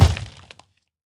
minecraft / sounds / mob / zoglin / step2.ogg